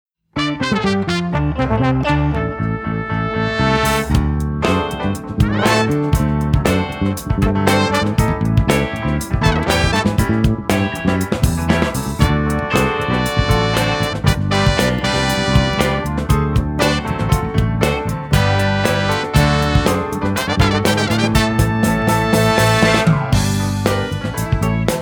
Listen to a sample of this instrumental song.